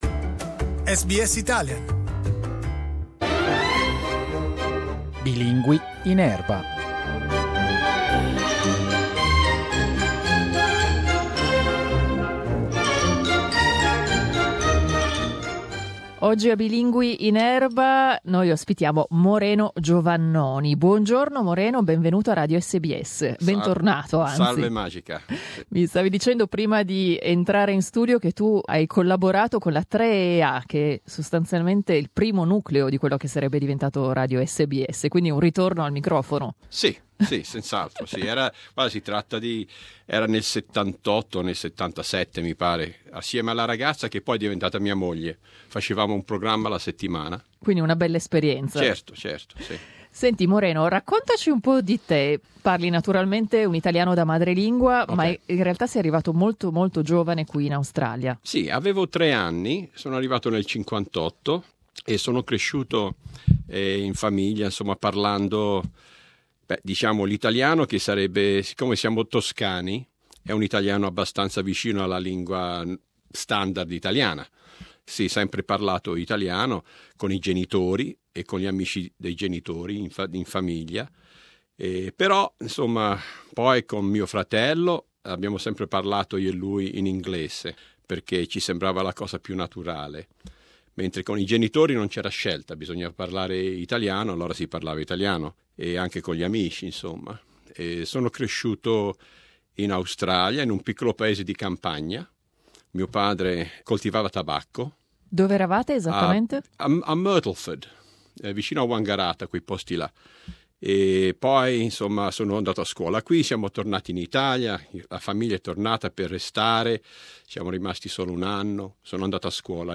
in our studios